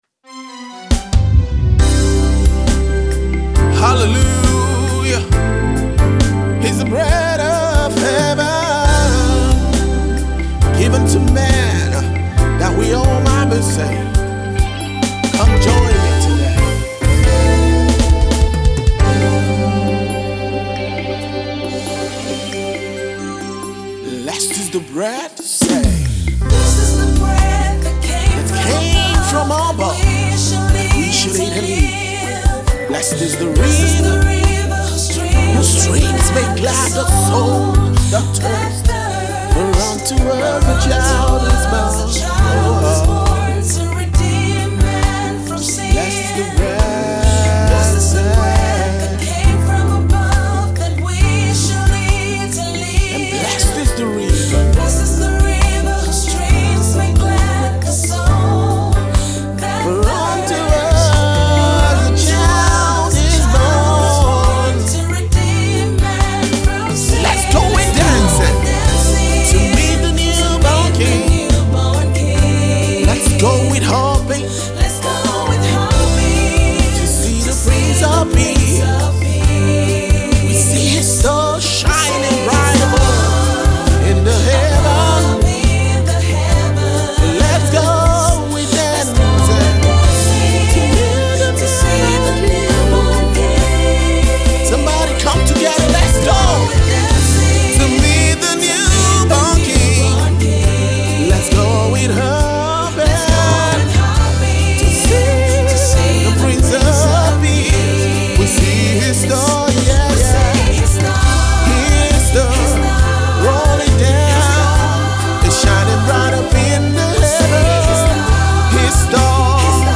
This season, gospel minister and worship leader,